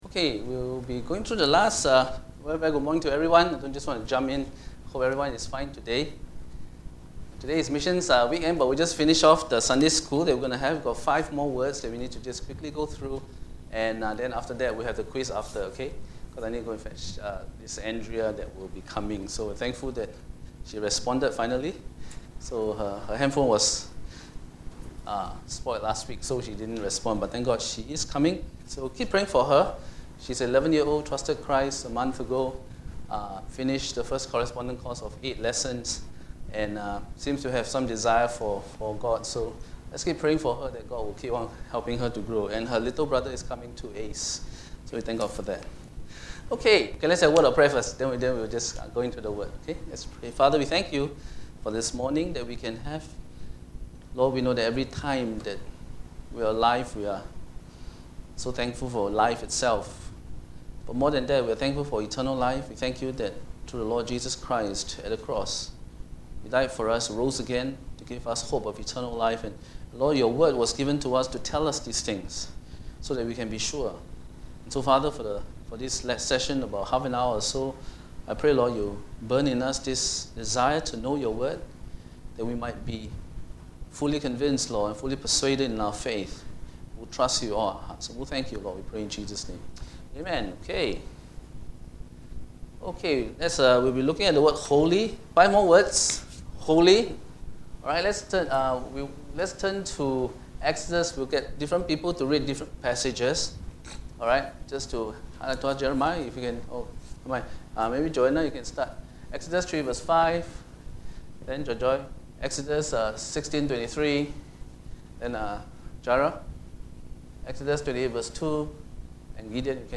Sunday Bible Hour 240818 We look at the next 5 words… 1. Holy/Sanctify 2. Hope 3. Glory 4. Mystery 5. Elect